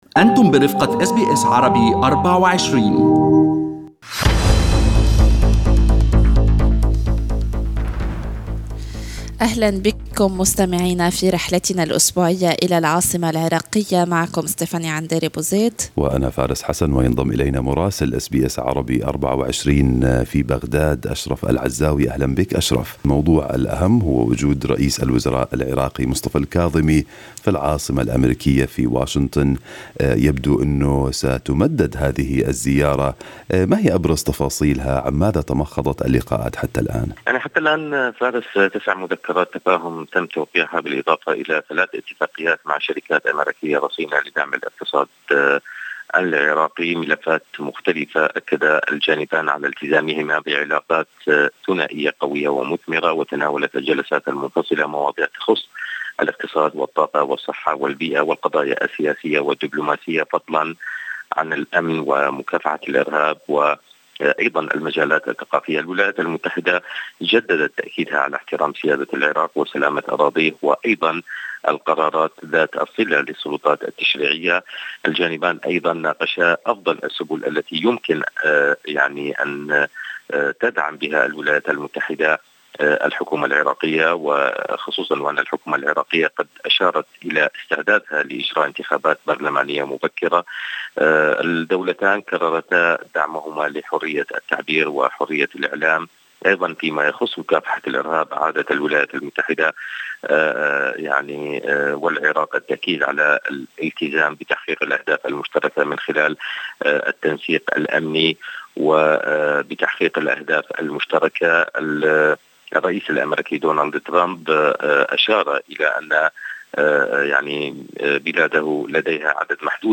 مراسلنا في العاصمة العراقية بغداد يلخص لنا في هذا البودكاست أبرز ما تمخض عن زيارة رئيس الوزراء مصطفى الكاظمي إلى واشنطن وطبيعة التفاهمات والاتفاقيات التي جرى توقيعها، وتفاصيل هجوم تنظيم داعش على مرقد ديني في محافظة صلاح الدين ما أوقع 6 قتلى وعدد من الجرحى.